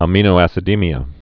(ə-mēnō-ăsĭ-dēmē-ə, ămə-)